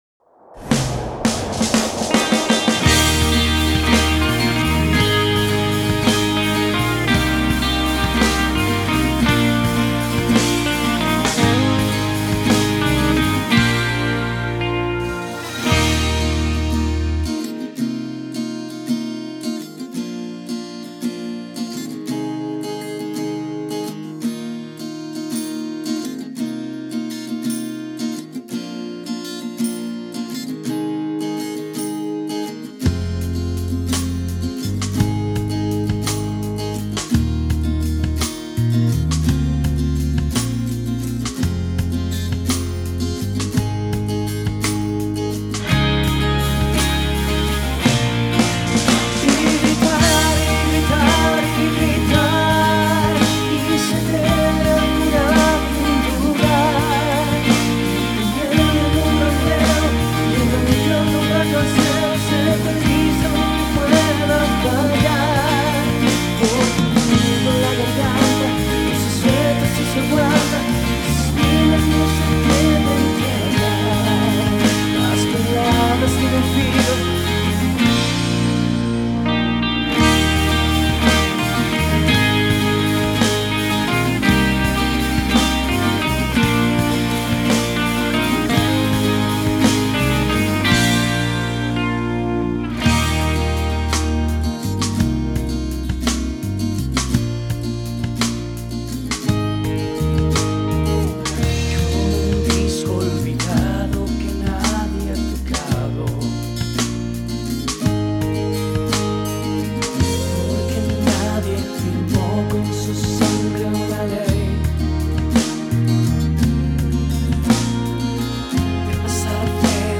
mp3 Скачать минус Похожие Смотри ещё